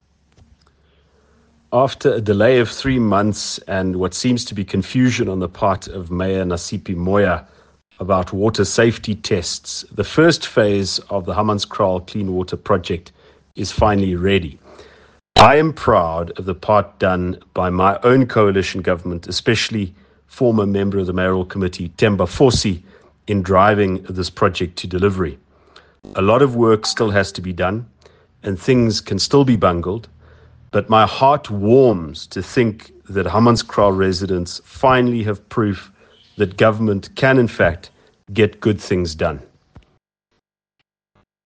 Issued by Ald Cilliers Brink – DA Tshwane Caucus Leader
Note to Editors: Please find an English soundbite by Ald Cilliers Brink